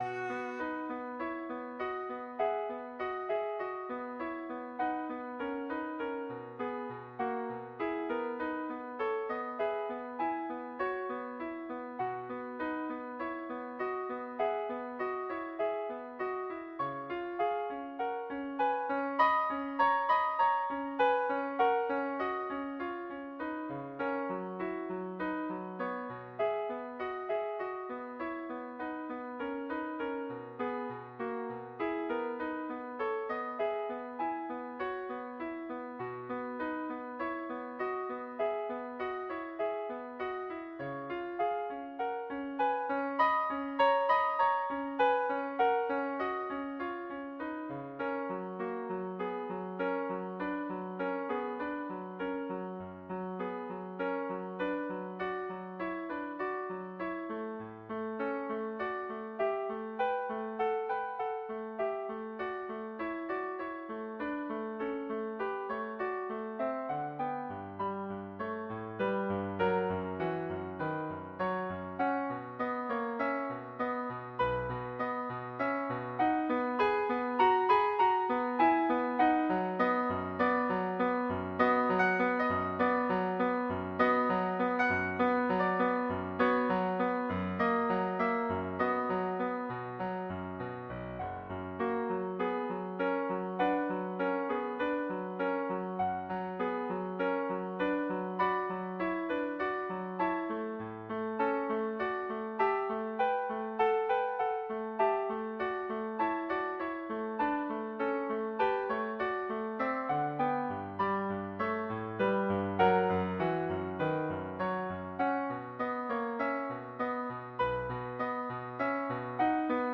Violin version
4/4 (View more 4/4 Music)
Classical (View more Classical Violin Music)